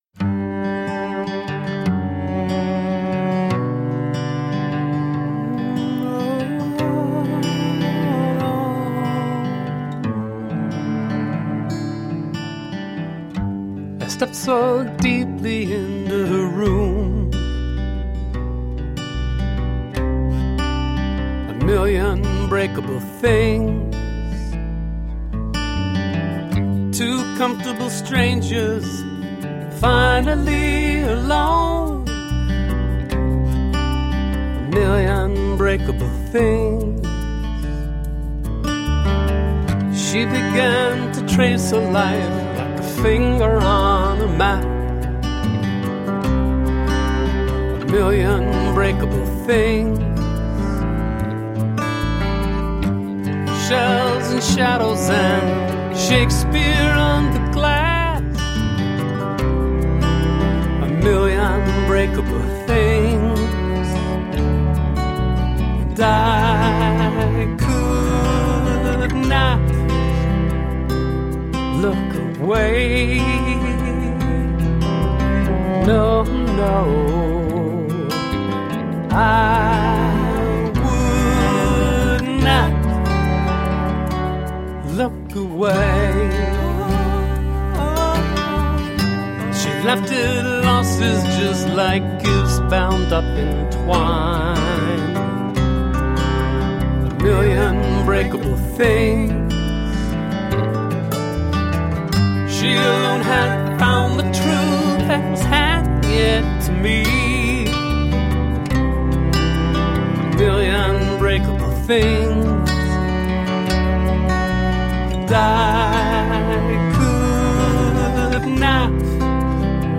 singer songwriter
Tagged as: Alt Rock, Rock, Country, Folk